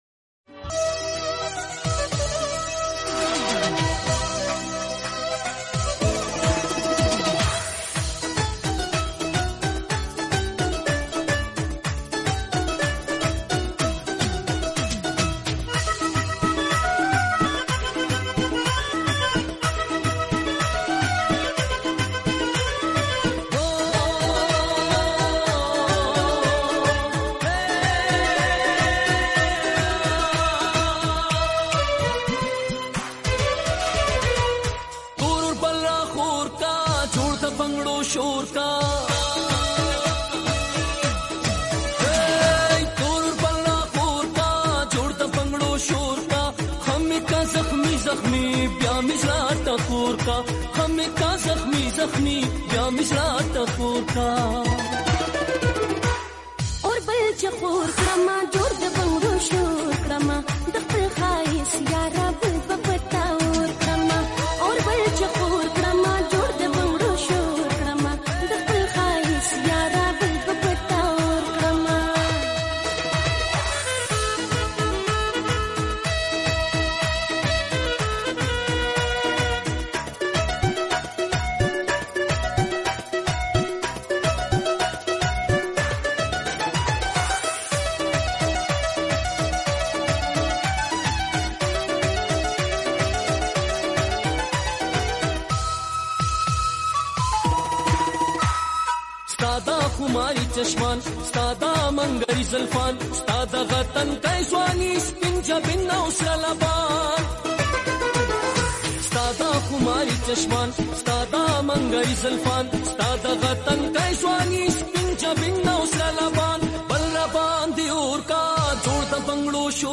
Pashto mast song